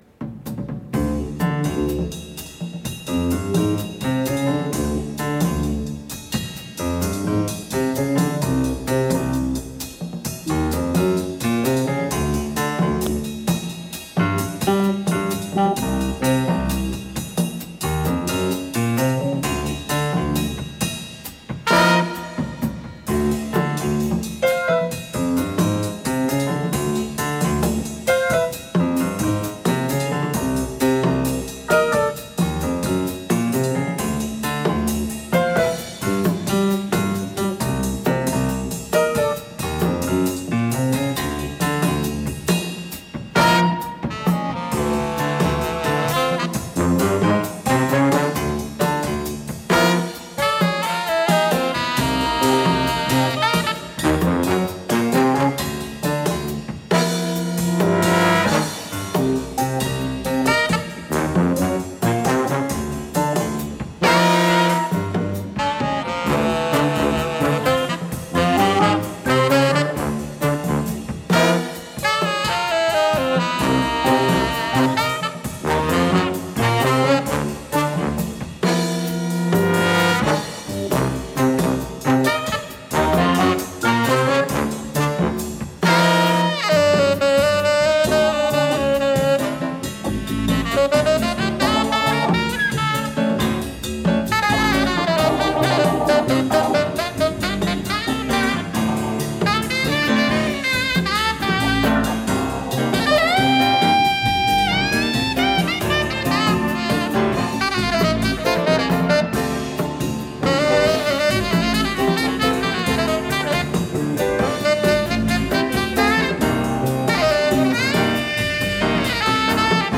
Studio 104, Maison de la Radio, Paris